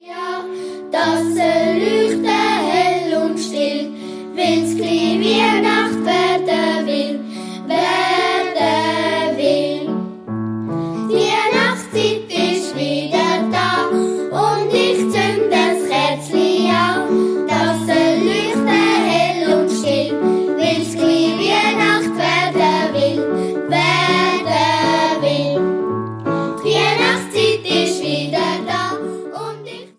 Alte Schweizer Weihnachtslieder sanft renoviert